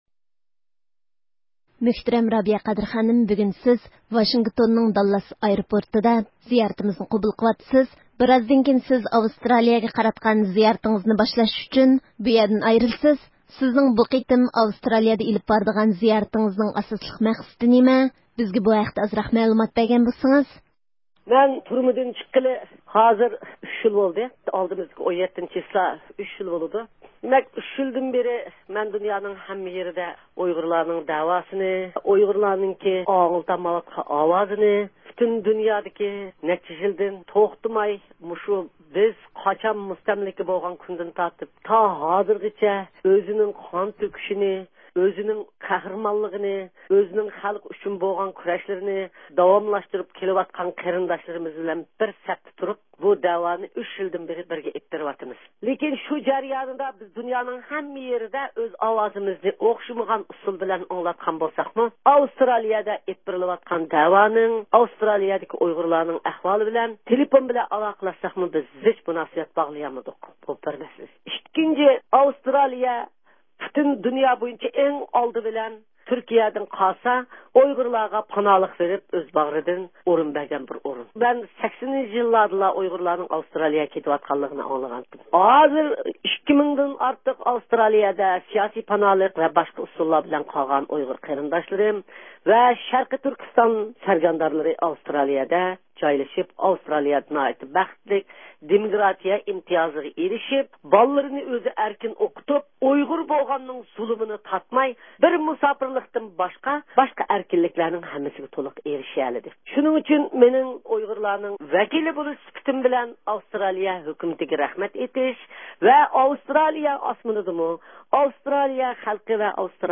رابىيە قادىر خانىم يولغا چىقىشتىن بۇرۇن ئىستانسىمىزنىڭ زىيارىتىنى قوبۇل قىلىپ، ئۆزىنىڭ بۇ قېتىمقى زىيارىتىنىڭ مەقسىدى ۋە زىيارەت پىلانلىرى ھەققىدە توختالدى.